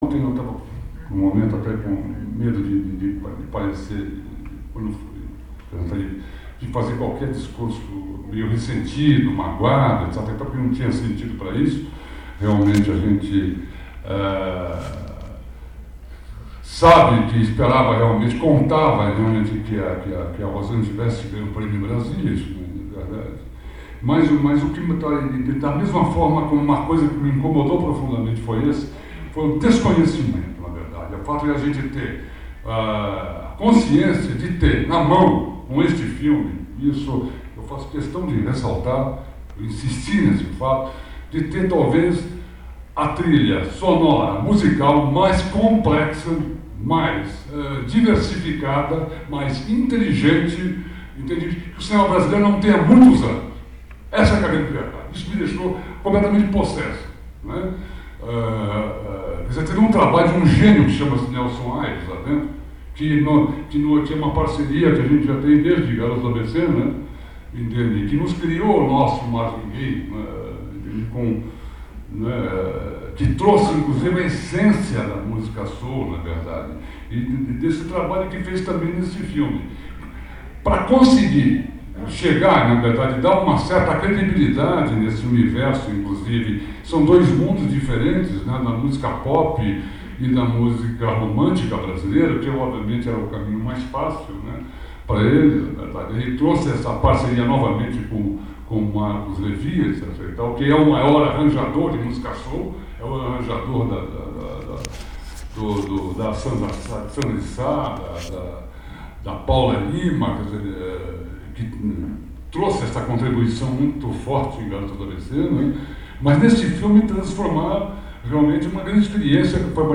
aqui e aqui) para ouvir a primeira intervenção de Carlão Reichenbach no debate de Falsa Loura. Ele começa falando de sua descoberta de Marvin Gaye a partir do contato com as operárias paulistas que renderam Garotas do ABC e tiveram seu desdobramento neste filme novo, para finalmente emendar no que ficara subentendido na homenagem do dia anterior – sua revolta não só pela não-premiação de Rosanne, mas, sobretudo, por Brasília também ter ignorado aquela que ele chama de "a mais inventiva trilha sonora do cinema brasileiro recente", de seu parceiro Nelson Ayres (no que, aliás, tem toda razão).